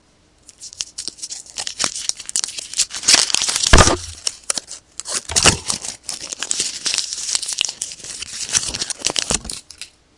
录制的糖果包装纸01
描述：从包装纸上打开一些糖果。
Tag: 糖果 包装